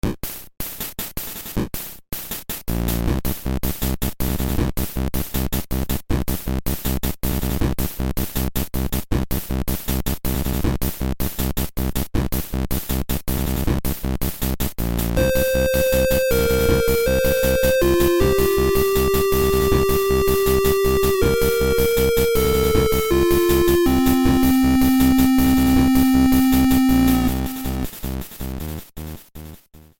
This is a sample from a copyrighted musical recording.